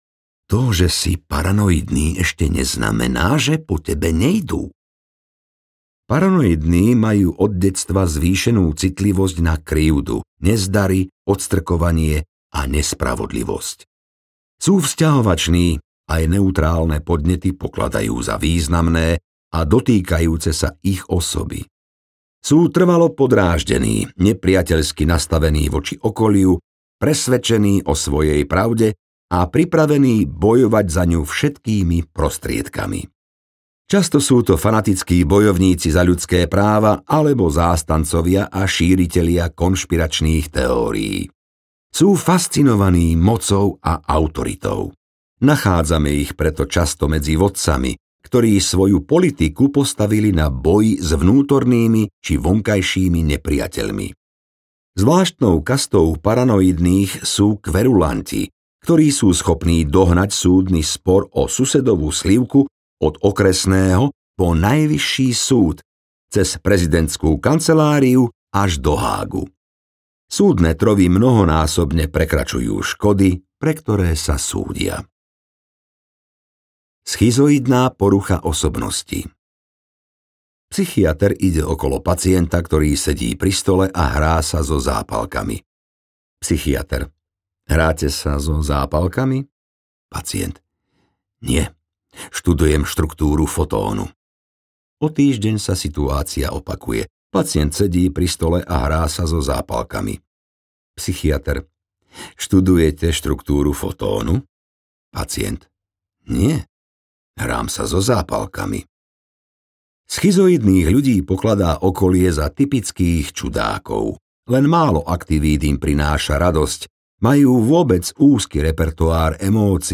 Humor audiokniha
Ukázka z knihy